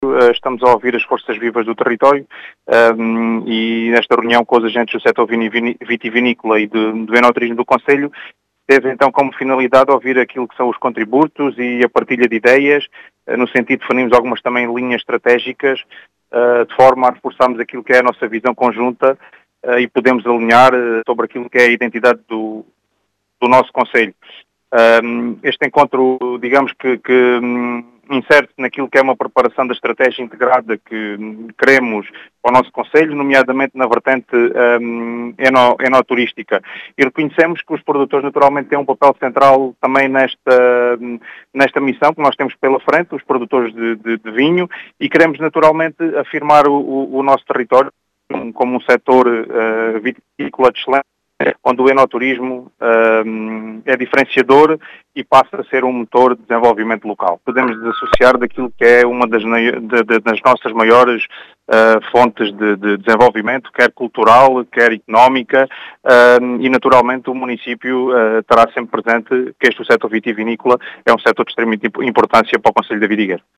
As explicações são do presidente da Câmara de Vidigueira, Ricardo Bonito, que quis ouvir um sector que é “estratégico” para o concelho, constituindo um “motor de desenvolvimento” para o concelho.